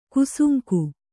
♪ kusuŋku